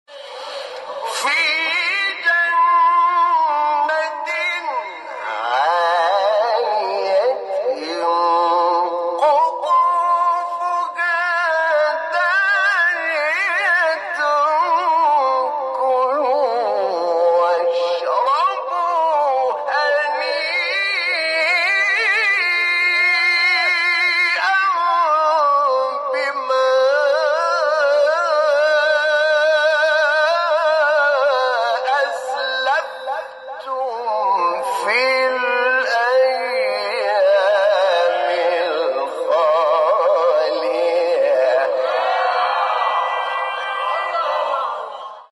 سوره : حاقه آیه : 22-24 استاد : حامد شاکرنژاد مقام : مرکب خوانی( رست * حجاز) قبلی بعدی